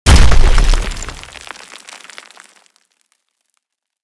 break.mp3